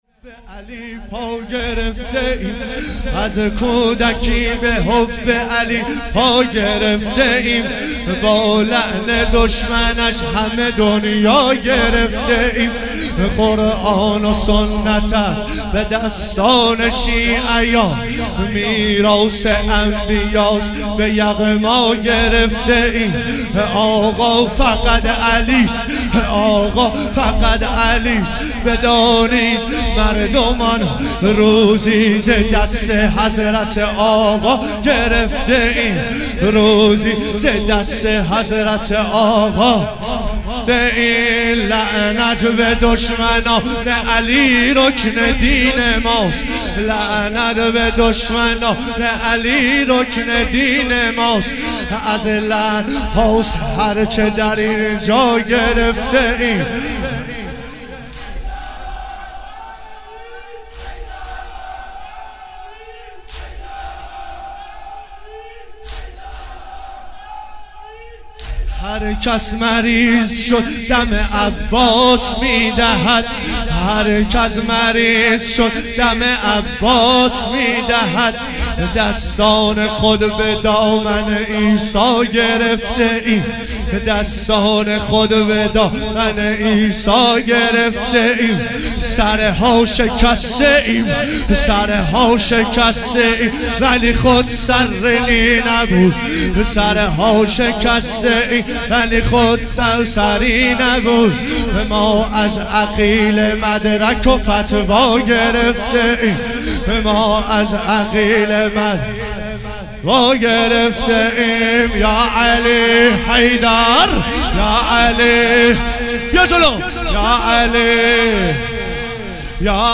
شب سوم محرم 96 - واحد تند